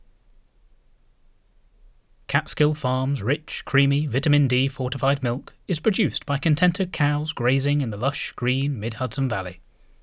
Mind checking my voice test?
But attached is a raw sample straight off the mic - I’m using a Rode NT-USB, 6ft cable, positioned slightly off to the side, supplied curved metal pop filter, in what is basically a blaknet fort on all four sides, no noticeably loud things in here, apart from my 2012 iMac which does have a slight ‘whirr’ but I’ve put a blanket around it (with a cut-out for the screen, just a barrier for the fan noise but they aren’t blocked) and even putting my head up to it I can’t make them out anymore.
I pass on peak, just fail on the RMS, and fail fairly well on noise level.
I can listen to a book in that voice.